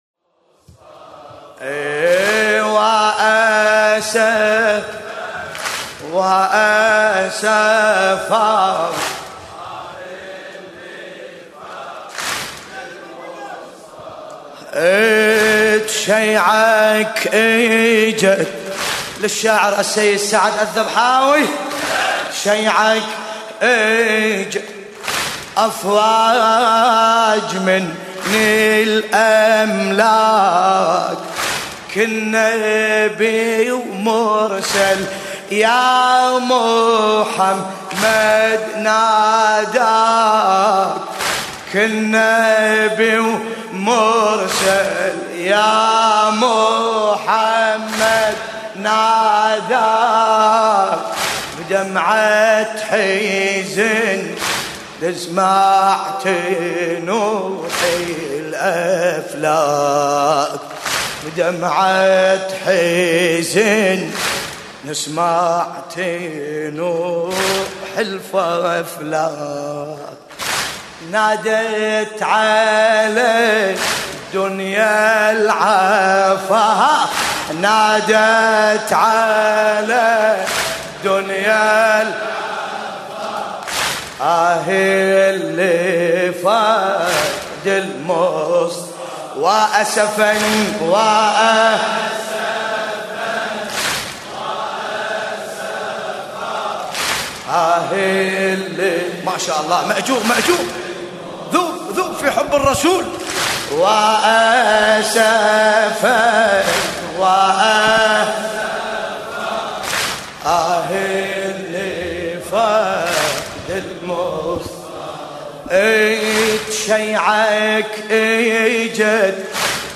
مراثي الرسول الأعظم (ص)